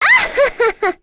Miruru-Vox_Happy2.wav